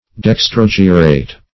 Search Result for " dextrogyrate" : The Collaborative International Dictionary of English v.0.48: Dextrogyrate \Dex`tro*gy"rate\, a. [Dextro- + gyrate.]